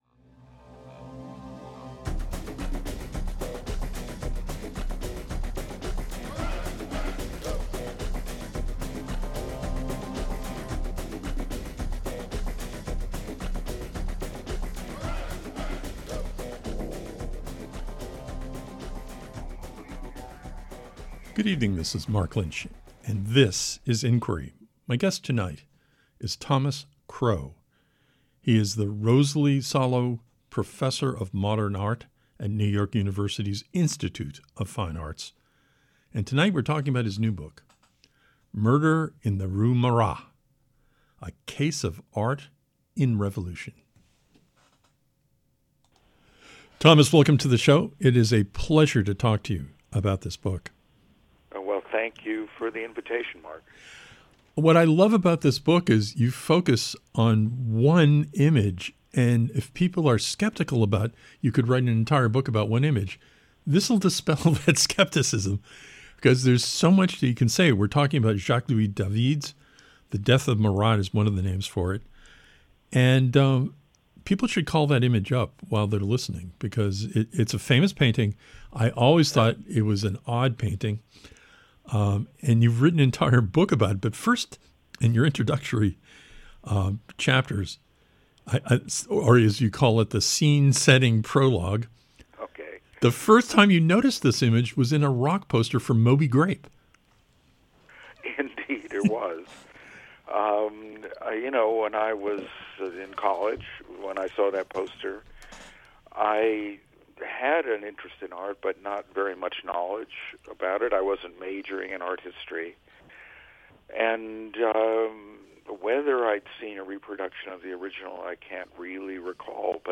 Tonight, our guest on Inquiry is THOMAS CROW.